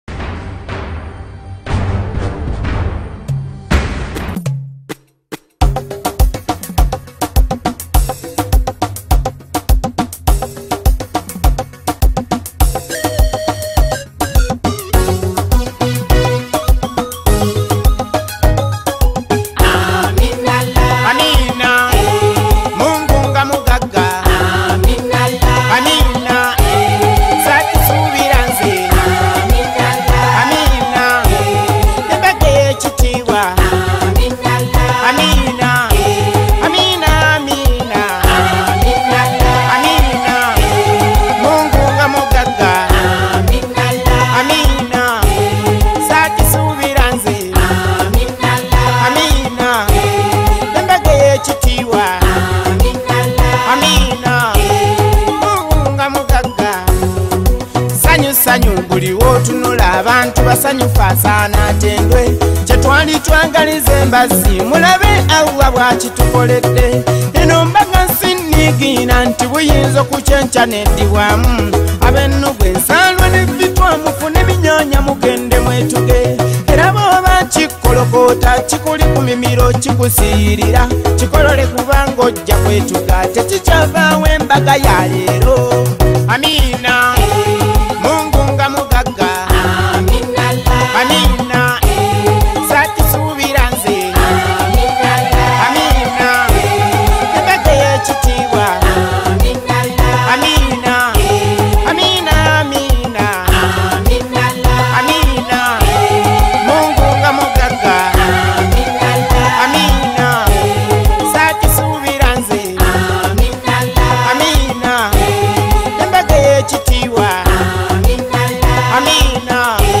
Genre: Oldies